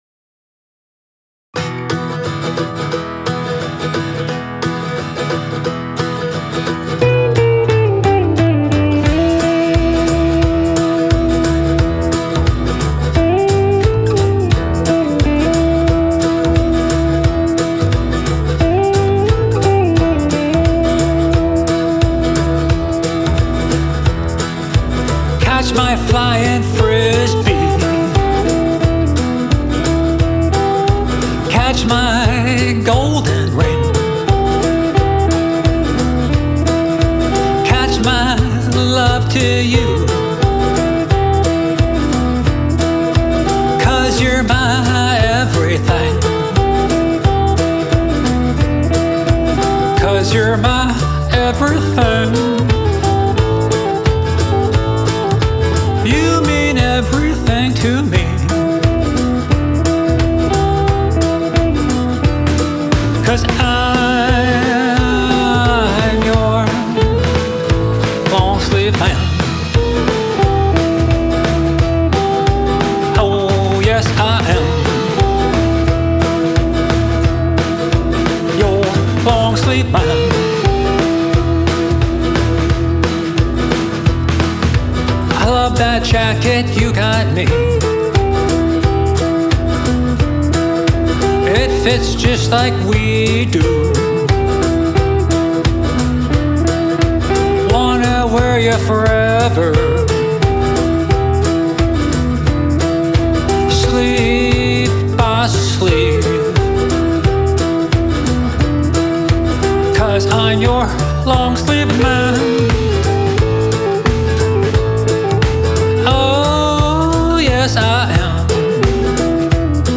Canadian singer/songwriter